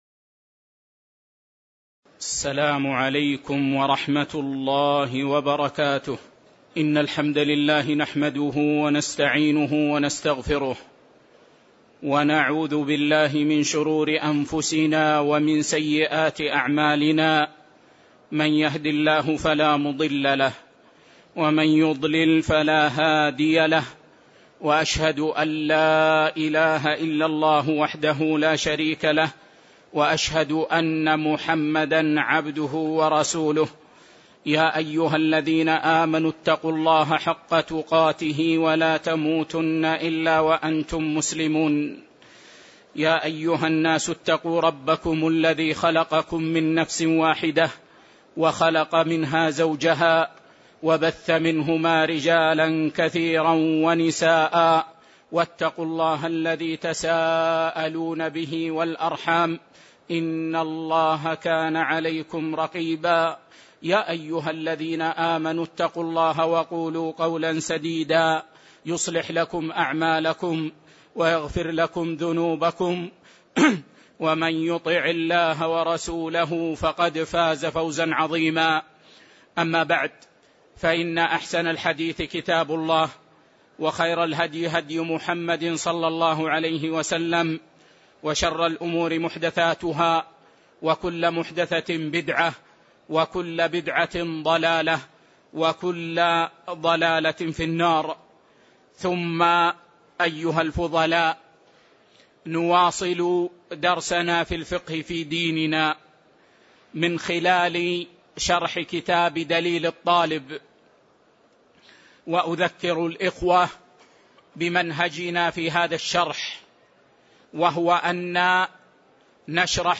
تاريخ النشر ٥ شعبان ١٤٣٧ هـ المكان: المسجد النبوي الشيخ